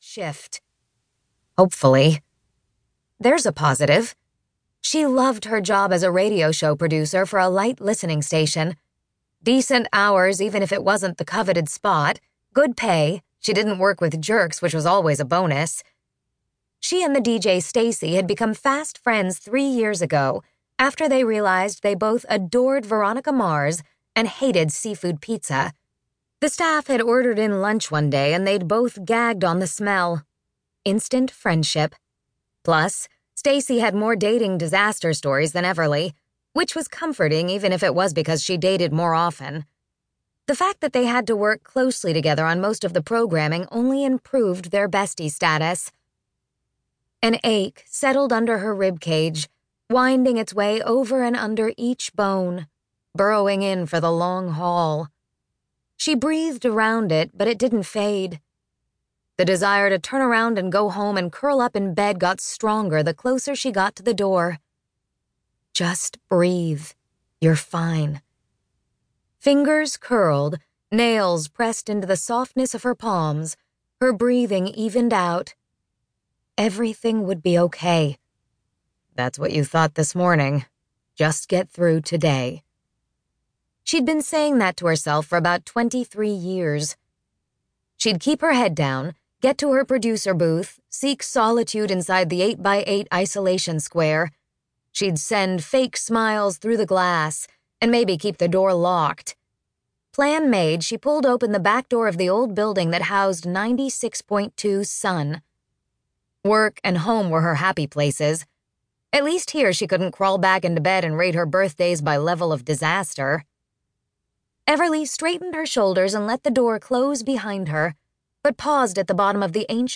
• Audiobook
A Macmillan Audio production from St. Martin's Griffin